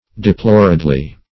deploredly - definition of deploredly - synonyms, pronunciation, spelling from Free Dictionary Search Result for " deploredly" : The Collaborative International Dictionary of English v.0.48: Deploredly \De*plor"ed*ly\, adv.